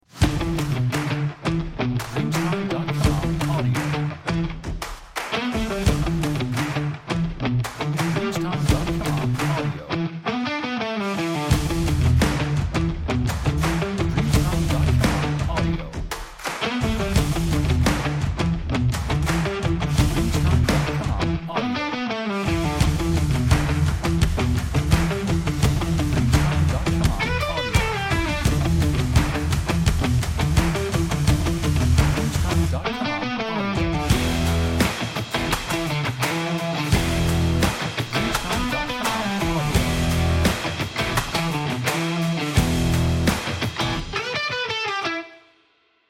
Stomp Rock